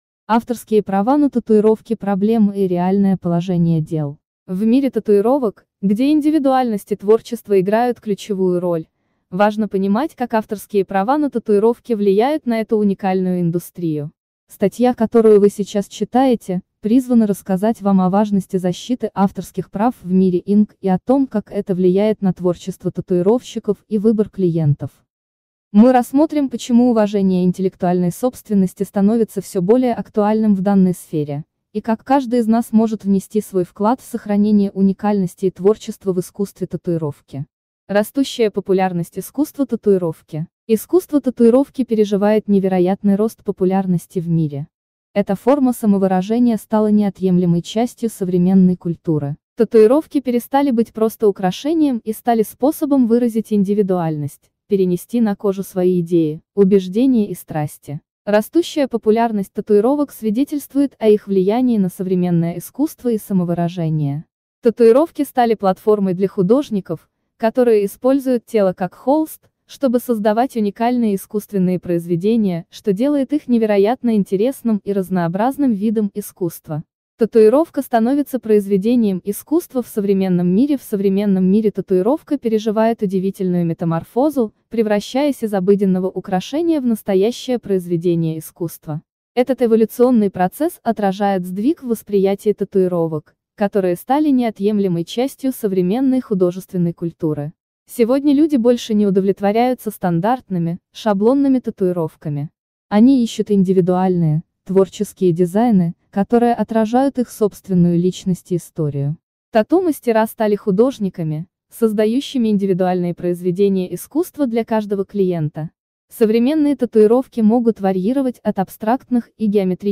2-Авторские-права-на-татуировки-аудио-версия-статьи-для-tatufoto.com_.mp3